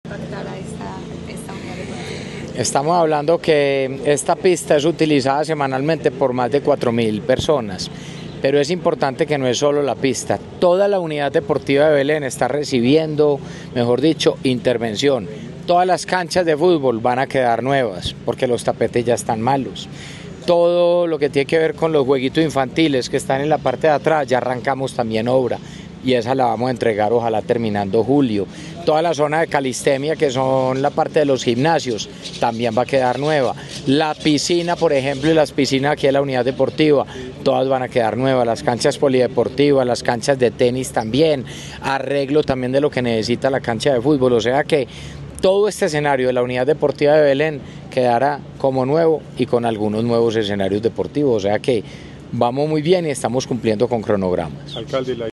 Audio Declaraciones del alcalde de Medellín, Federico Gutiérrez Zuluaga (intervención 1)-1 Audio Declaraciones del alcalde de Medellín, Federico Gutiérrez Zuluaga (intervención 2) Audio Declaraciones del alcalde de Medellín, Federico Gutiérrez Zuluaga (intervención 3)
Audio-Declaraciones-del-alcalde-de-Medellin-Federico-Gutierrez-Zuluaga-intervencion-2.mp3